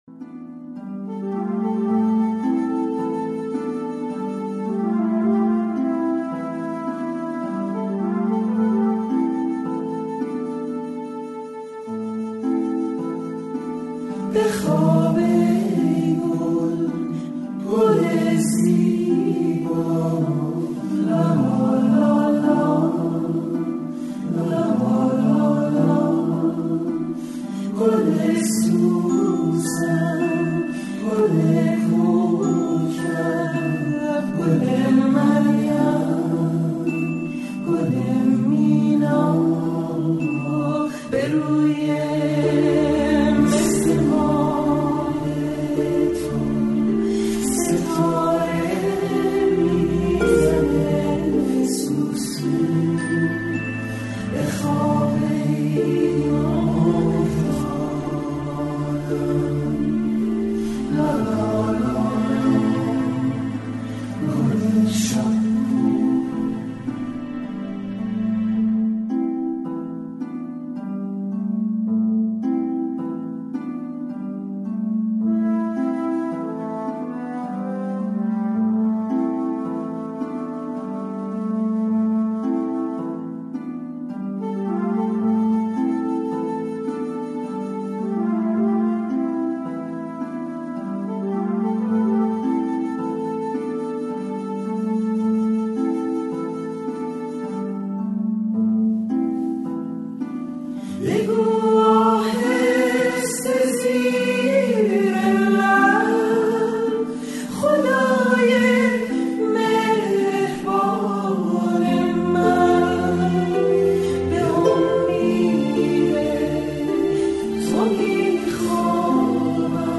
لالایی لالا لالا گل زیبا
آهنگ لالایی